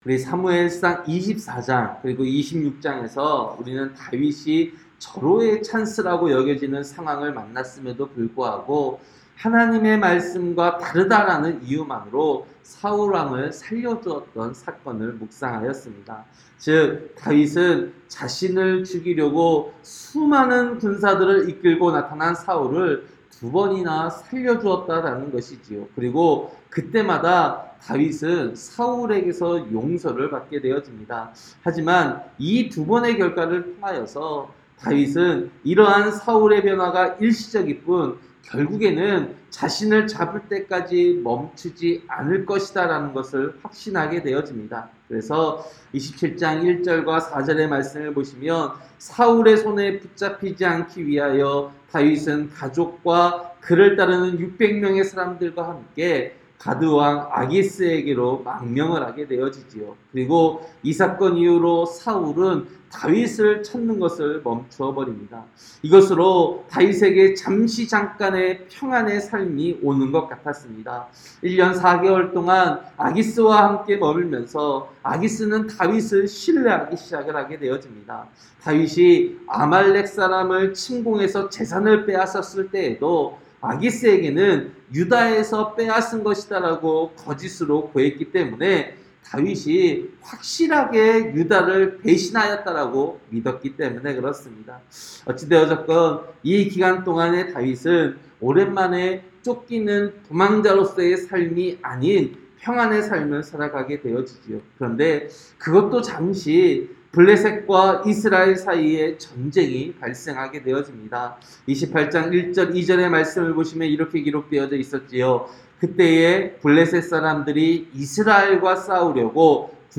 새벽설교-사무엘상 29장